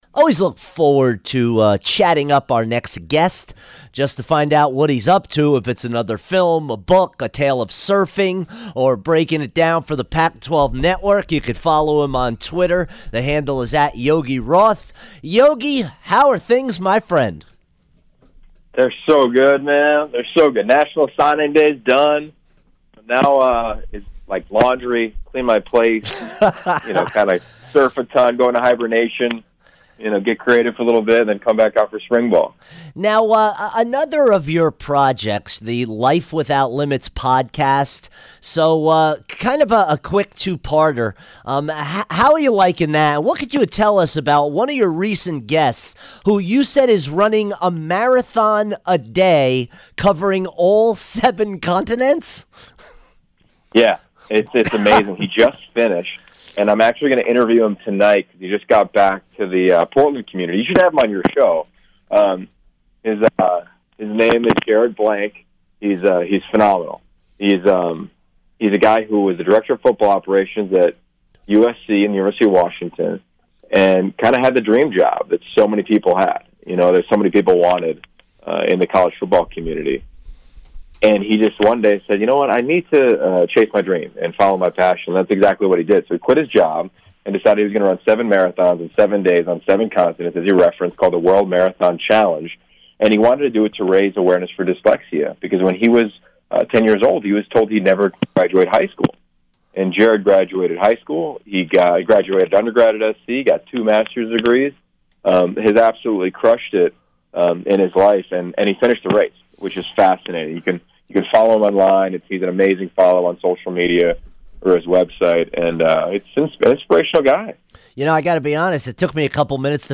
Yogi Roth Interview 2-8-18